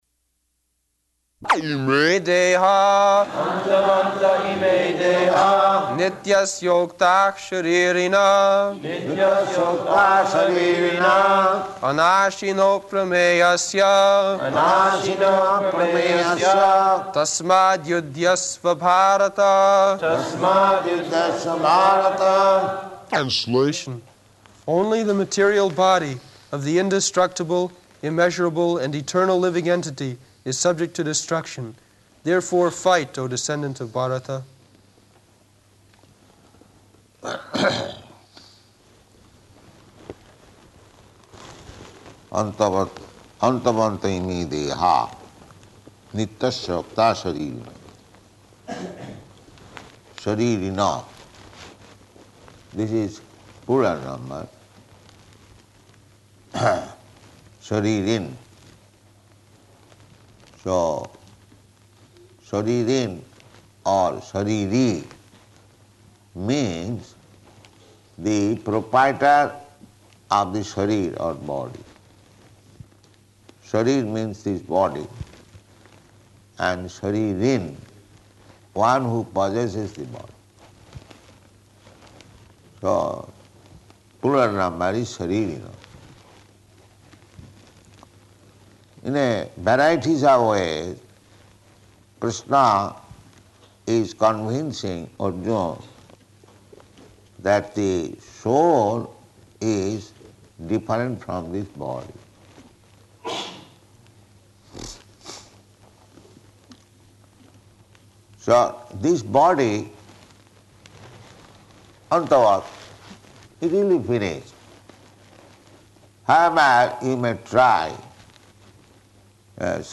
Location: London
[leads chanting of verse] [Prabhupāda and devotees repeat]